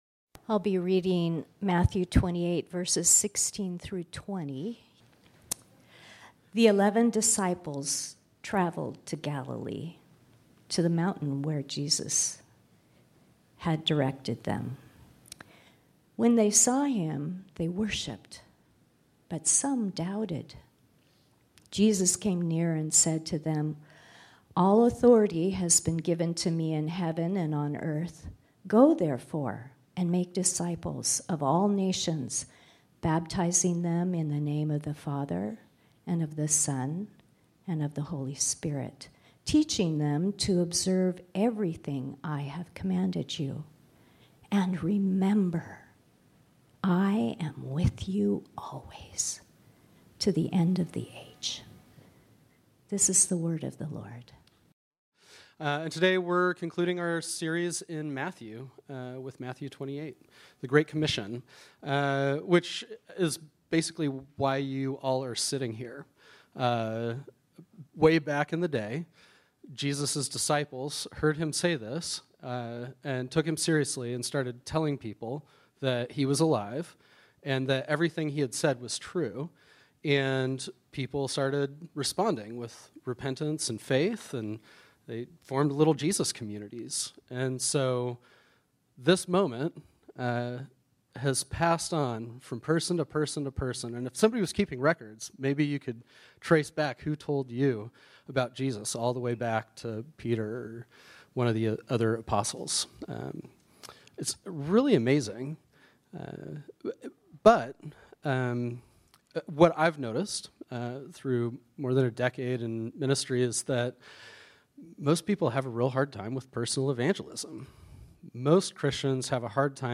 ” our sermon series on the Gospel of Matthew.